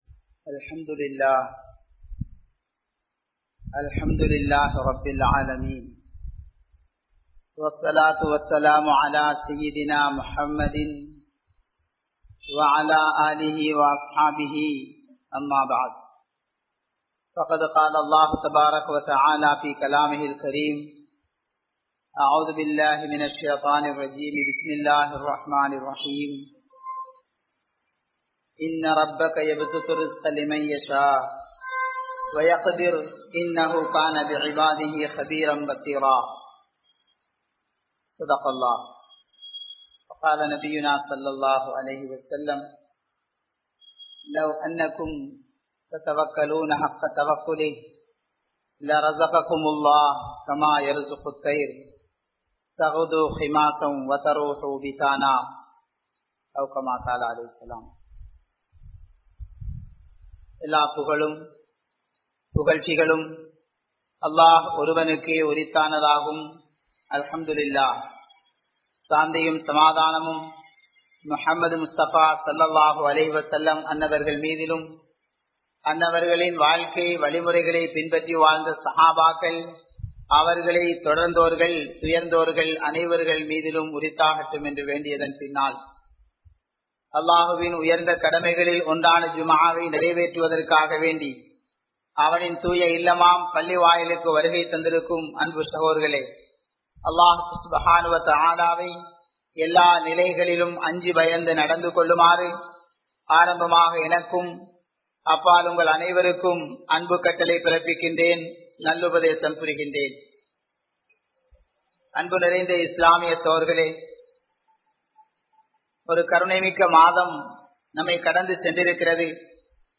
Thaqwa & Thawakkal (தக்வா & தவக்கல்) | Audio Bayans | All Ceylon Muslim Youth Community | Addalaichenai